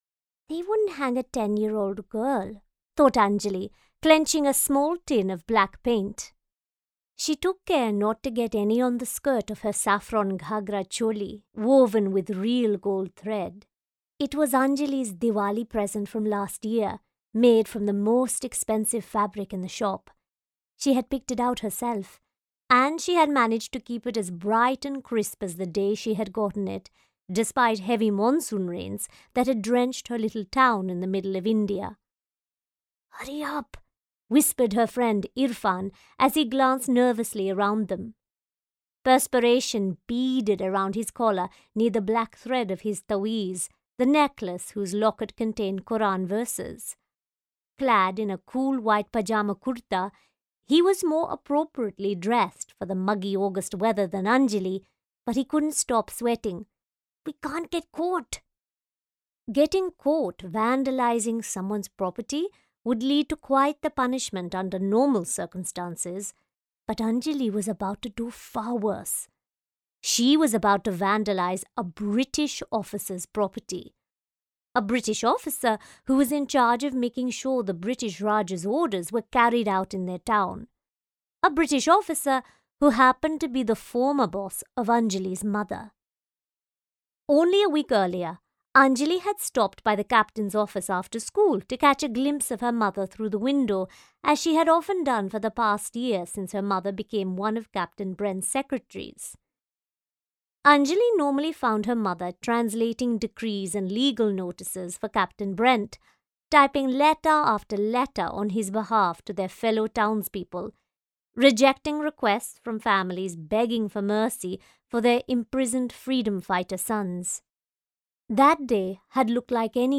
Big news!